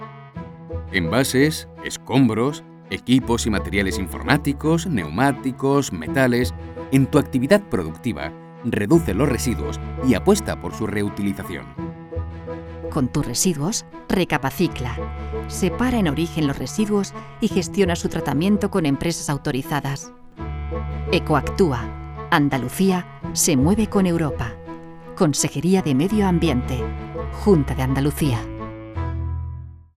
Cuña de Radio sobre gestión de residuos en empresas